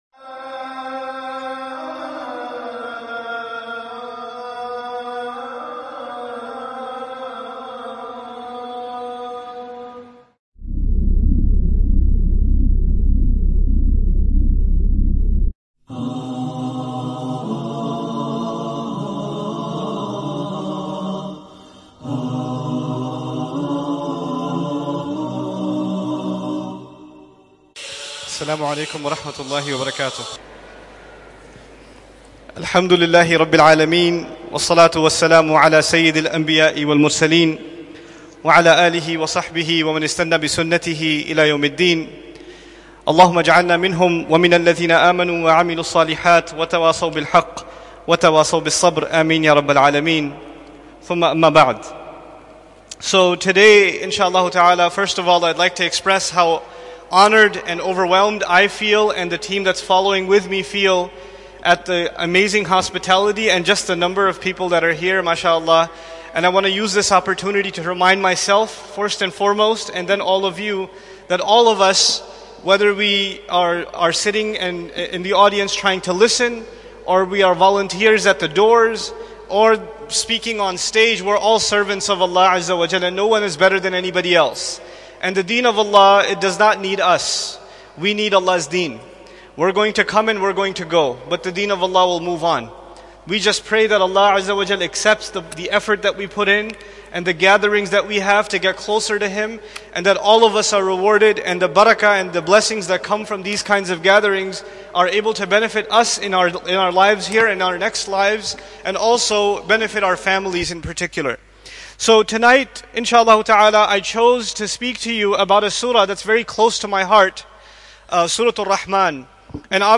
First Lecture during the 2013 Malaysian Tour. An introduction, really, to Surah Ar-Rahman. The lecture was made 5th September 2013 at the Wilayah Mosque, Kuala Lumpur, Malaysia.